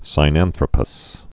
(sī-nănthrə-pəs, sĭ-, sīnăn-thrōpəs, sĭnăn-)